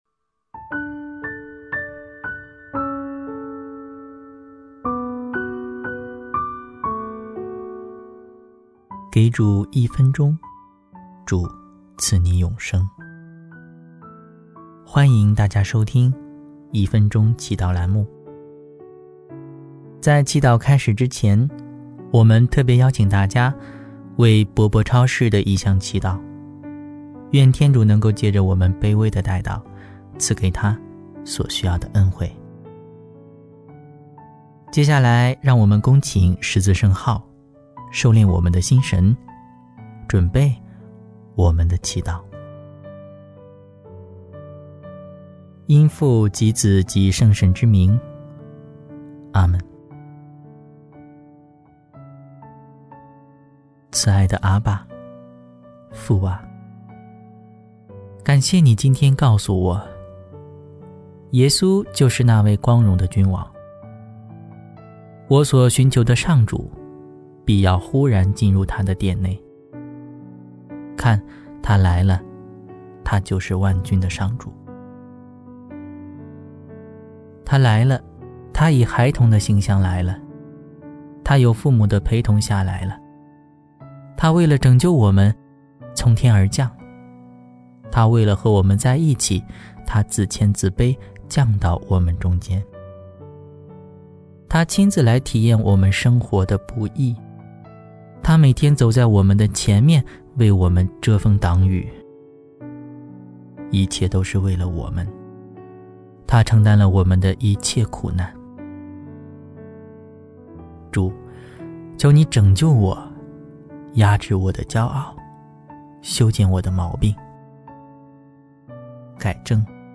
【一分钟祈祷】|主，求你拯救我，压制我的骄傲！（2月2日）